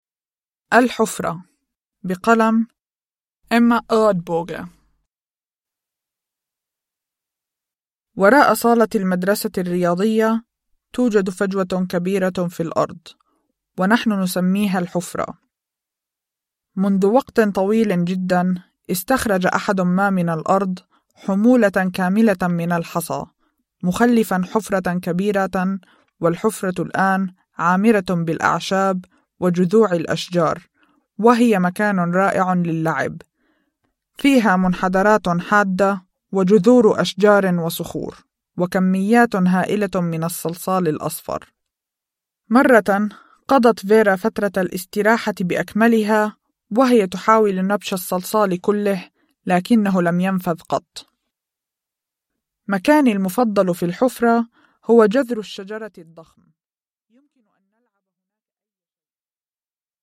Gropen (arabiska) – Ljudbok – Laddas ner